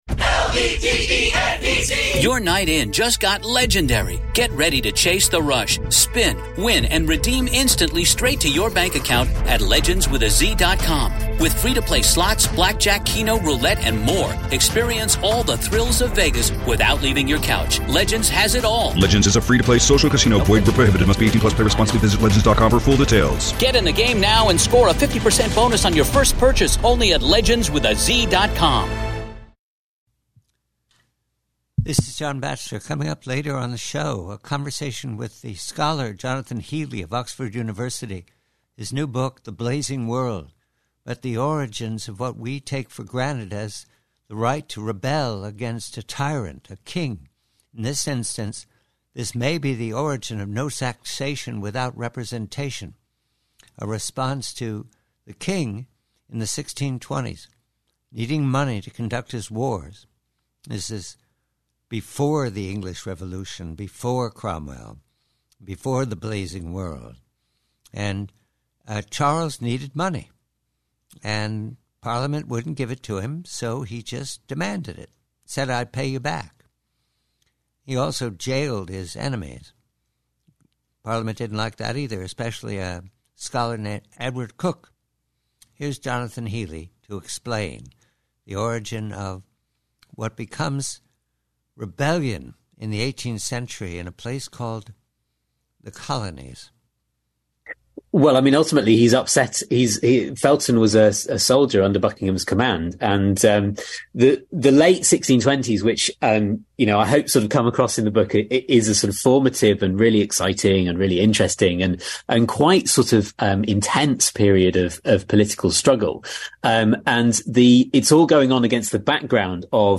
PREVIEW: From a two-hour conversation later with Oxford history scholar